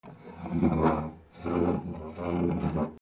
テーブルを動かす 03
引きずる